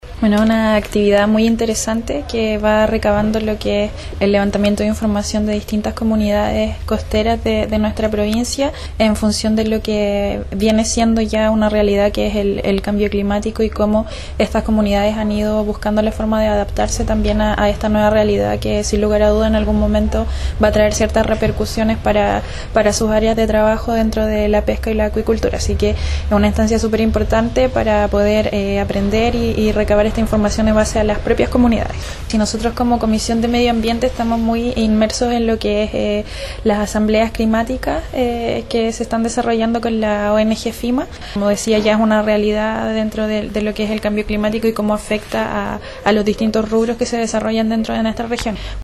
En tanto palabras similares tuvo la Consejera Regional, Daniela Méndez, quien añadió en el Gobierno Regional de los Lagos, ya se encuentra trabajando el tema del cambio climático y por lo mismo la experiencia desarrollada en Chiloé, puede ser una instancia de aprendizajes para abordar una materia que ya no es ajeno para nadie: